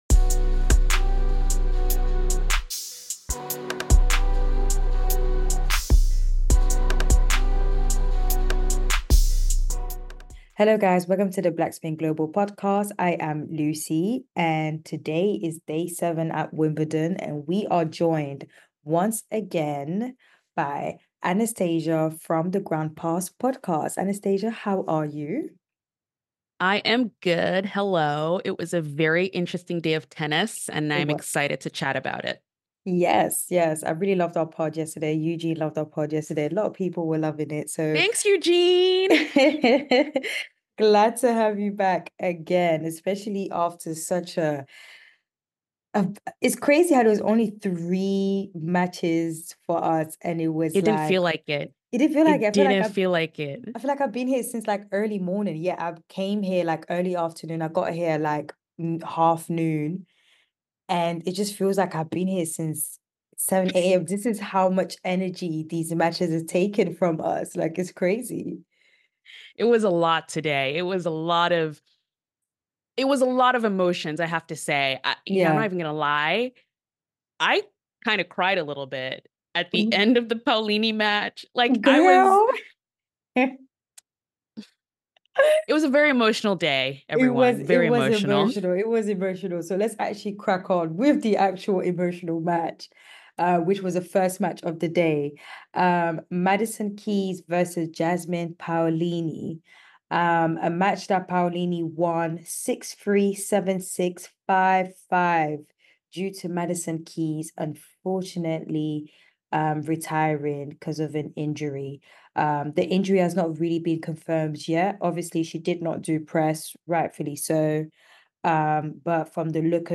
Listen out for interview snippets from Gauff, Shelton and a funny one from Paolini.